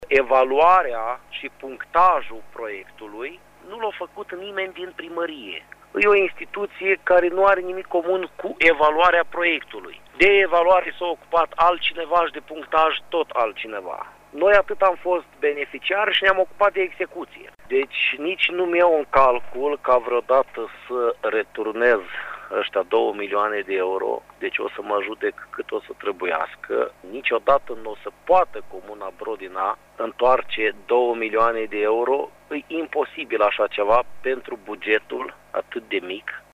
Primarul de Brodina, Vasile Viorel Melen, susţine că suma reprezintă bugetul localităţii pe 20 de ani, în cazul în care taxele şi impozitele ar fi încasate în totalitate.